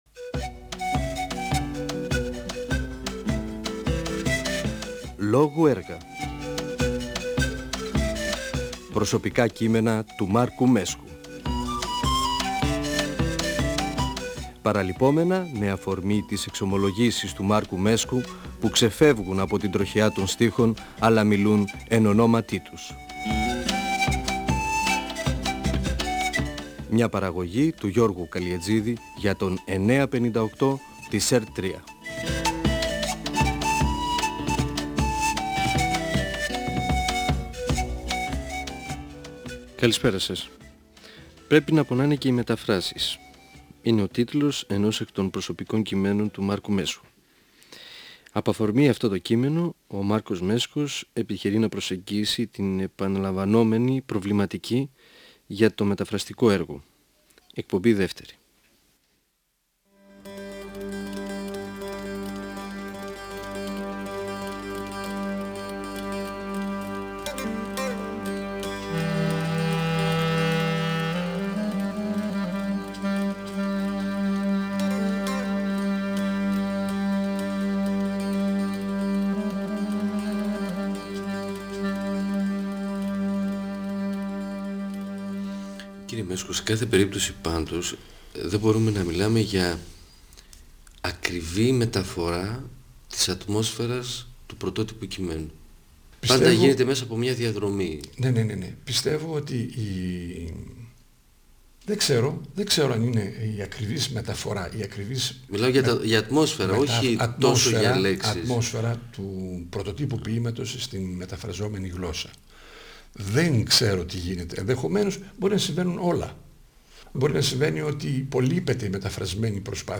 Ο ποιητής και δοκιμιογράφος Μάρκος Μέσκος (1935-2019) μιλά για τη σημασία και τις δυσκολίες τής μετάφρασης ενός λογοτεχνικού έργου (εκπομπή 2η)